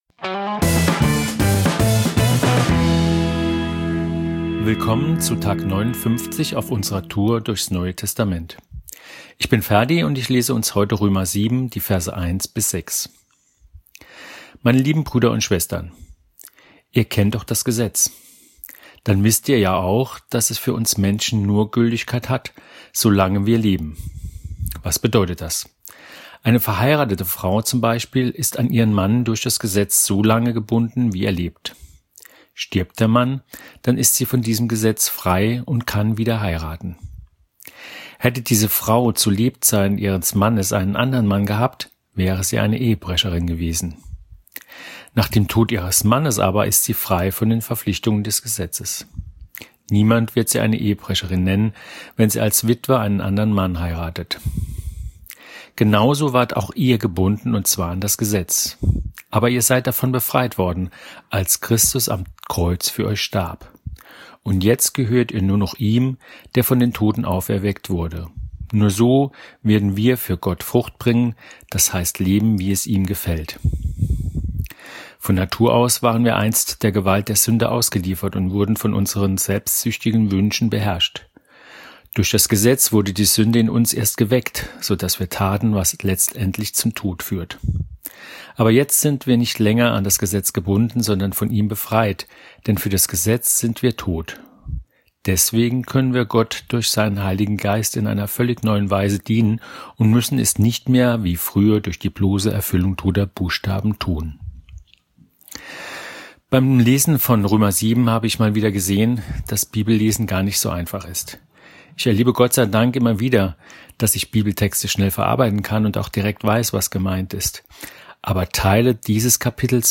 38 Menschen aus unserer Kirche lesen kleine Abschnitte je eines Kapitels aus den Evangelien, der Apostelgeschichte und den Briefen des Neuen Testaments.